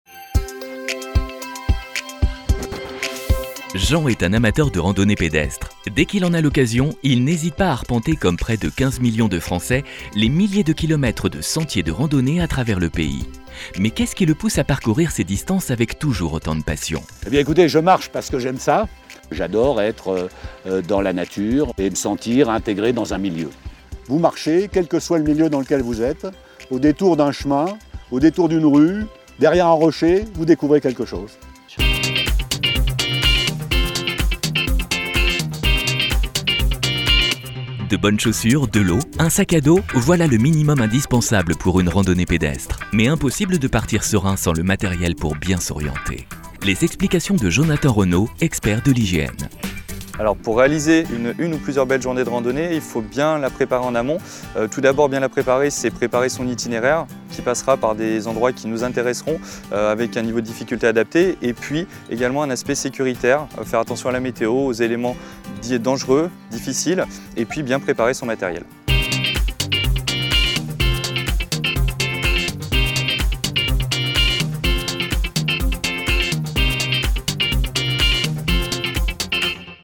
IGN informatif, bienveillant - Comédien voix off
Genre : voix off.